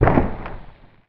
DEMOLISH_Short_04_mono.wav